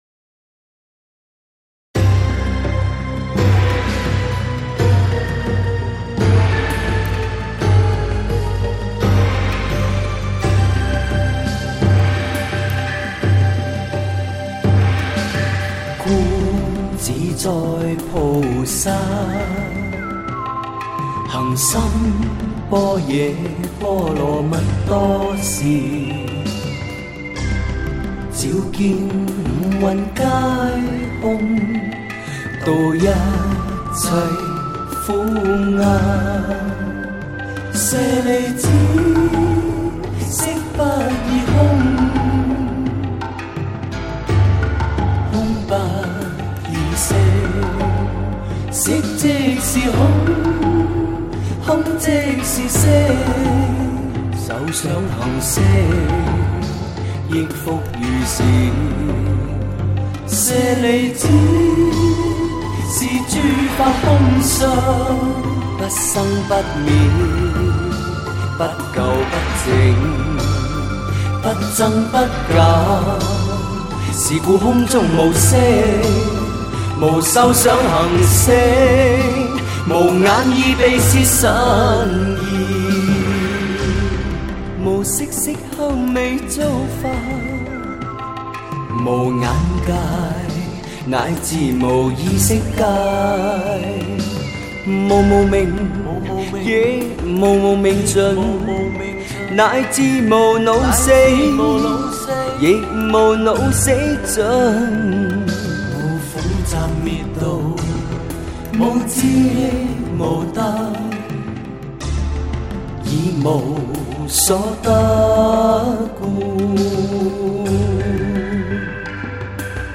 佛音 诵经 佛教音乐